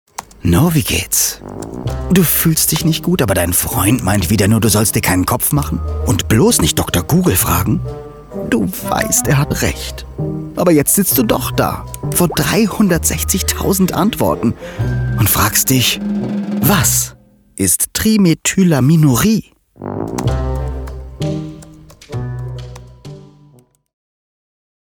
markant, dunkel, sonor, souverän
Mittel minus (25-45)
Commercial (Werbung)